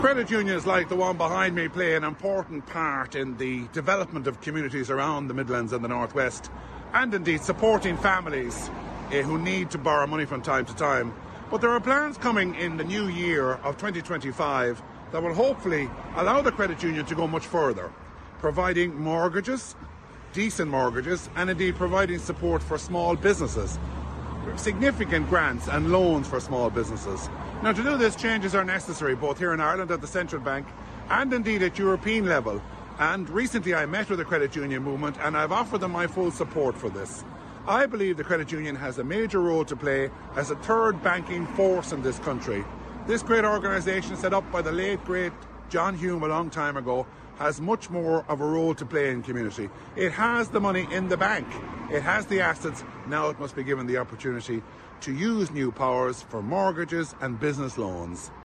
Mr Mullooly says Credit Unions must be given the opportunity to exercise their lending power: